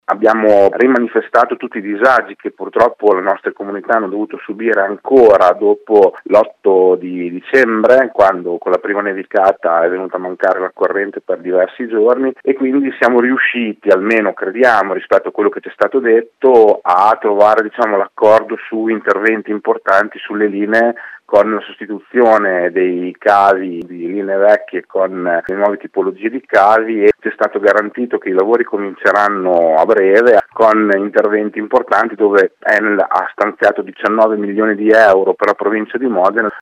Sentiamo il presidente della provincia Fabio Braglia